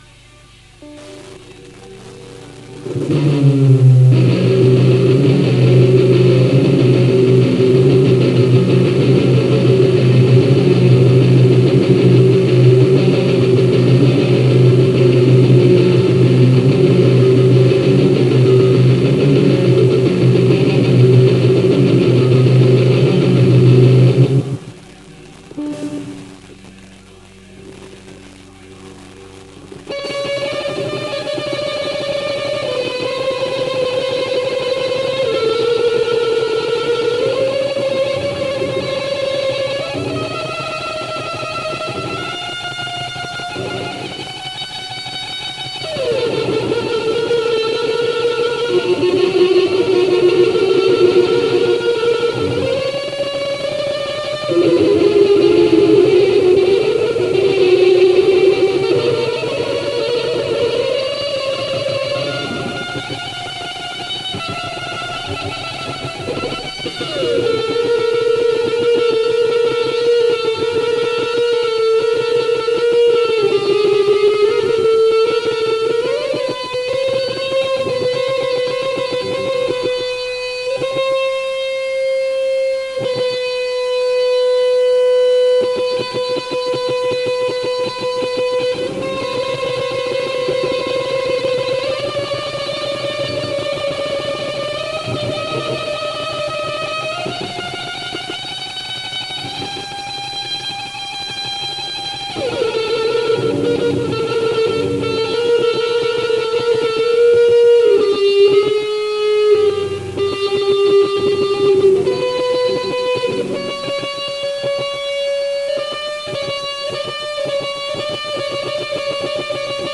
Eigenes Solo, das ich 3 min. nachdem ich die Gitarre hatte, gespielt habe, demnach ist es auch noch total billig ...